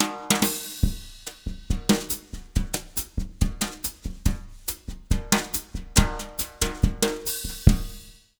140BOSSA06-R.wav